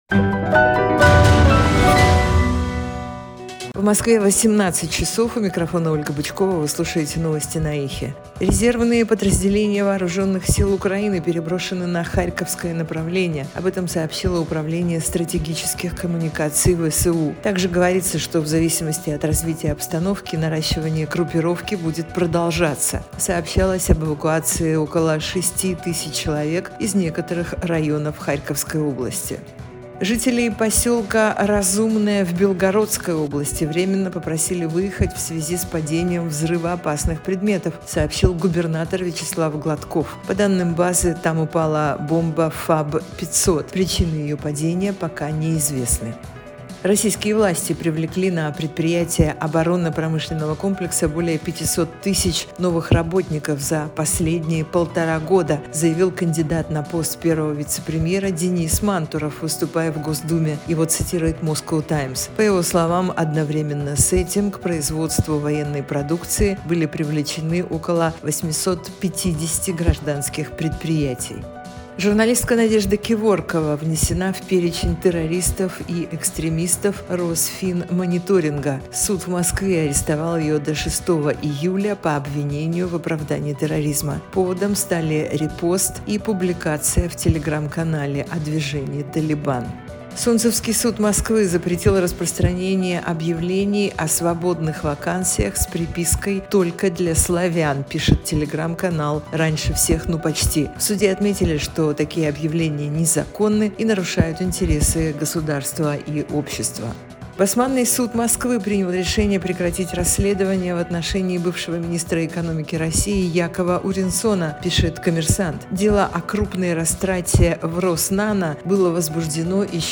Слушайте свежий выпуск новостей на «Эхе»
Новости